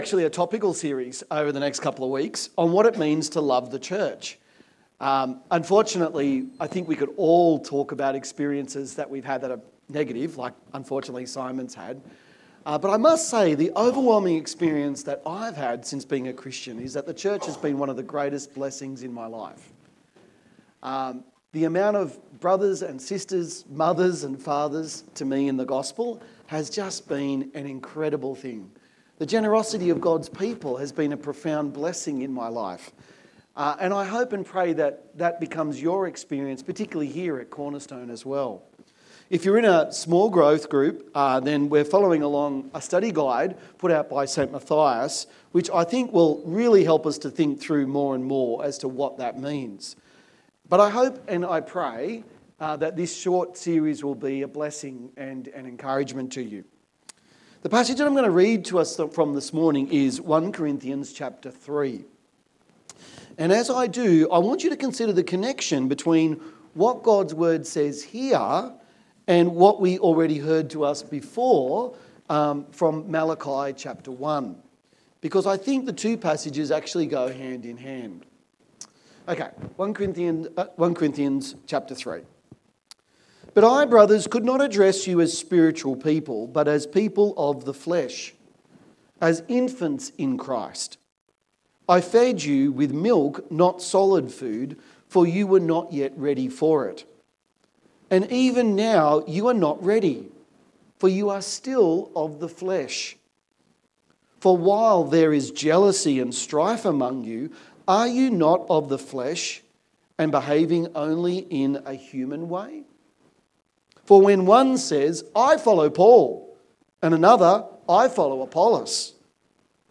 Text: Psalm 133:1-3; 1 Corinthians 3:1-17 Sermon